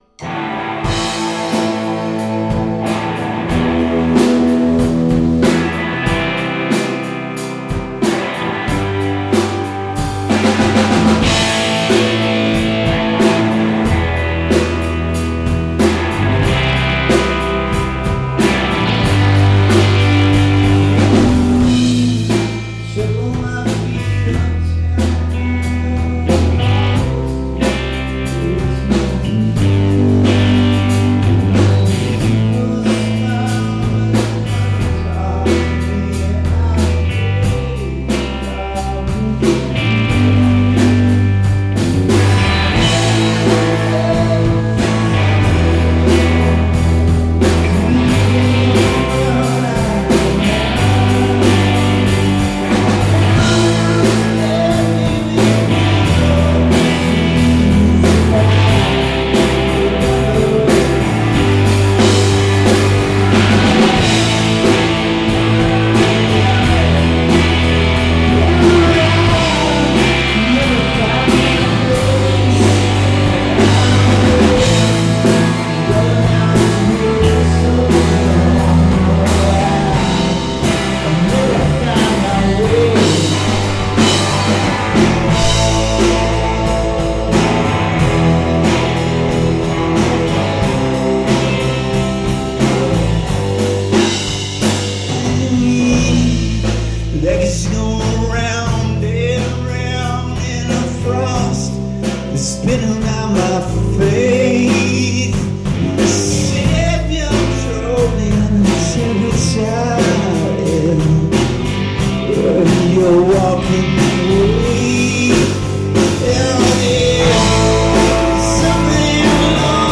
102513 riff idea